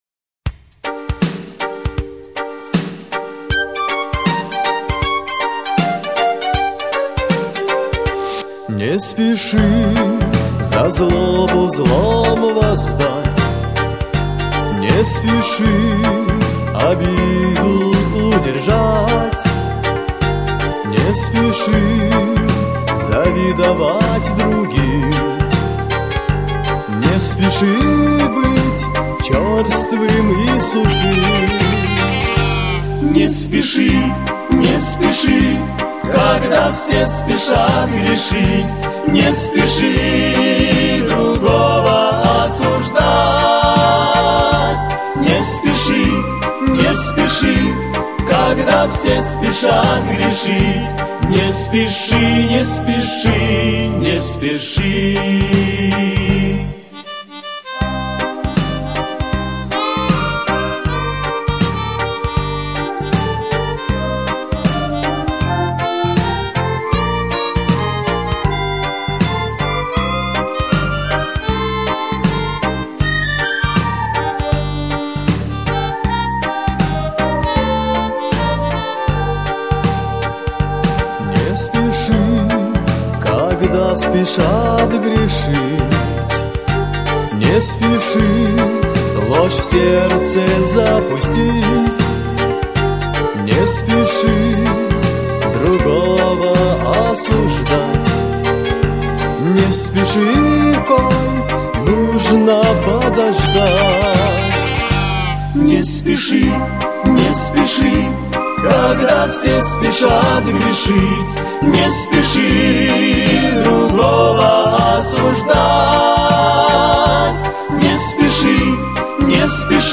285 просмотров 862 прослушивания 81 скачиваний BPM: 76